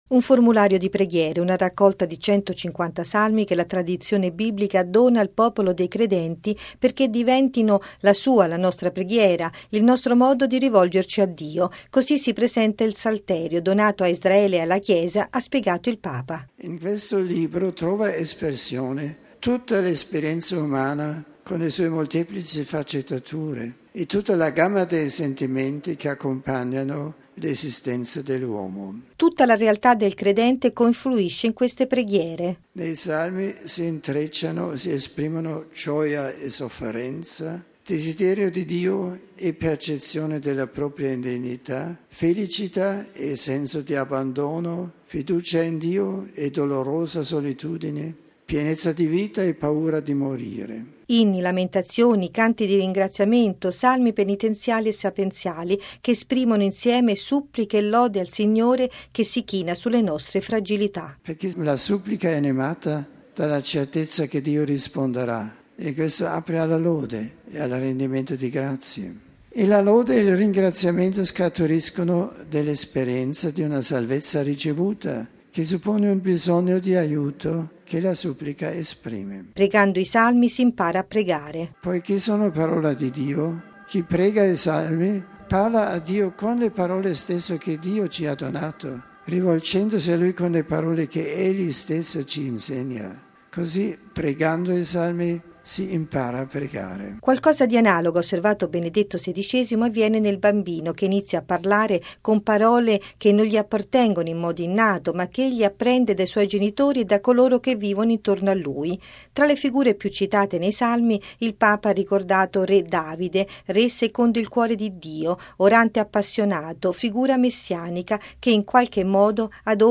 Benedetto XVI all’udienza generale: i Salmi insegnano a pregare con le parole di Dio
◊   Lasciamoci insegnare da Dio come pregarlo: l’invito è stato rivolto stamane da Benedetto XVI all’udienza generale in piazza San Pietro, dedicata al “libro di preghiera per eccellenza”, il libro dei Salmi.